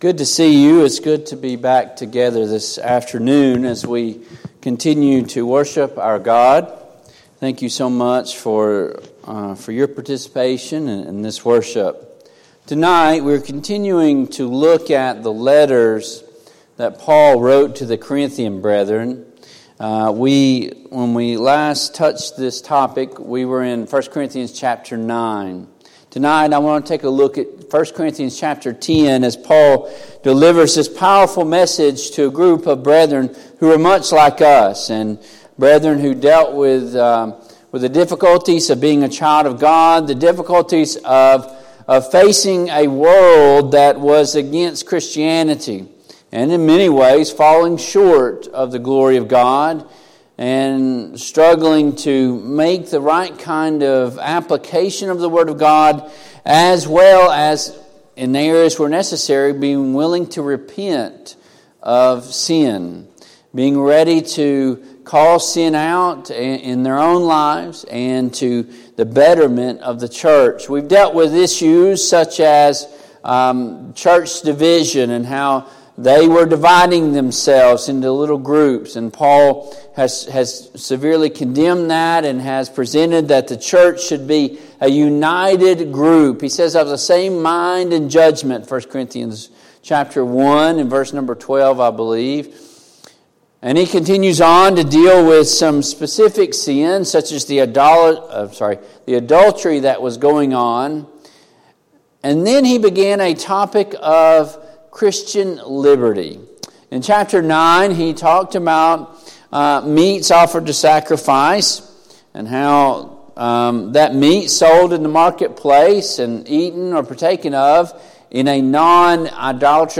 Service Type: PM Worship